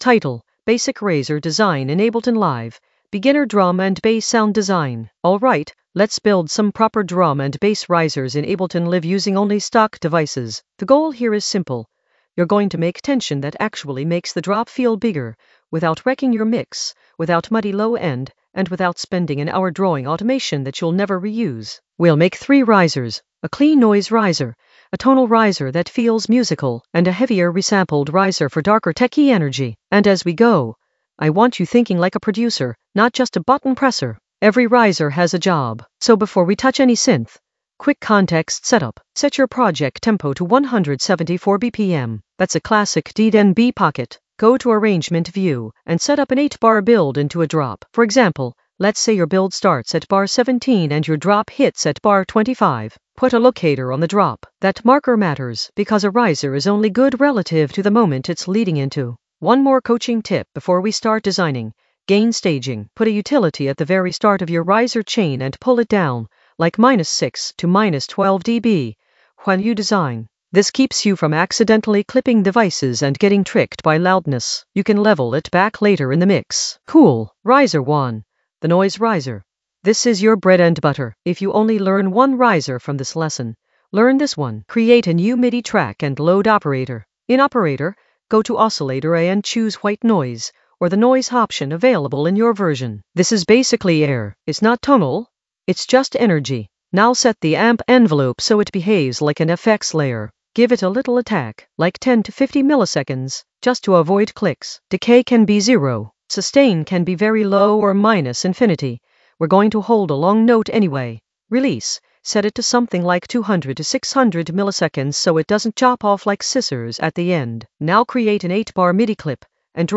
An AI-generated beginner Ableton lesson focused on Basic riser design in Ableton in the Sound Design area of drum and bass production.
Narrated lesson audio
The voice track includes the tutorial plus extra teacher commentary.